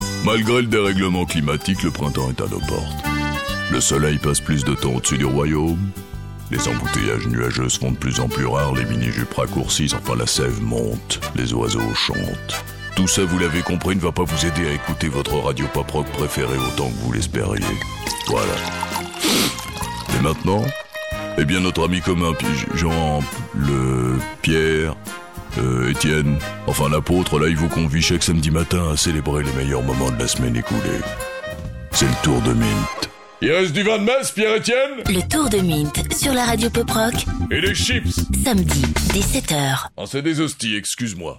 Profundo, Natural, Maduro, Cálida, Suave